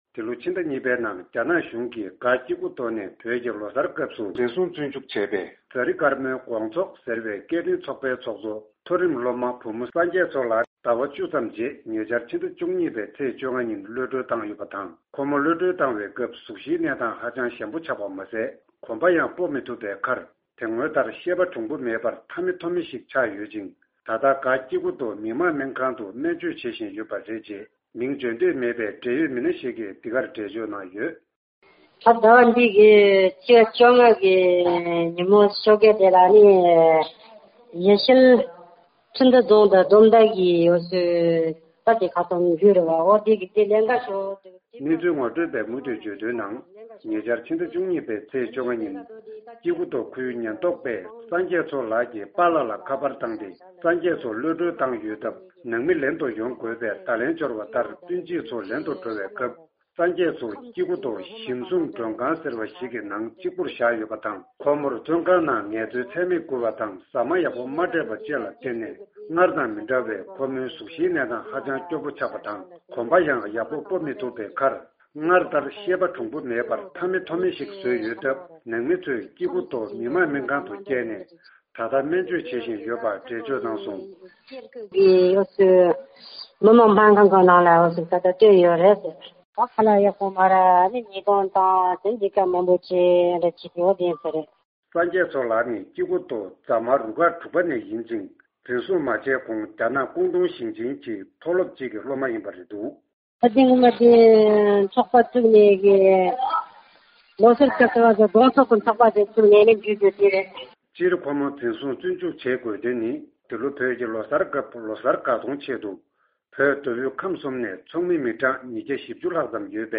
སྙན་སྒྲོན་ཞུས་ཡོད་པར་གསན་རོགས།